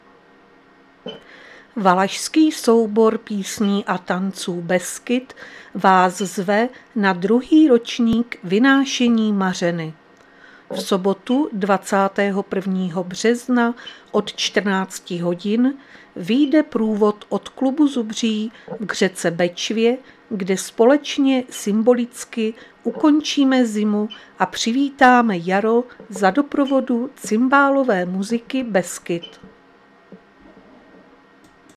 Záznam hlášení místního rozhlasu 19.3.2026
Zařazení: Rozhlas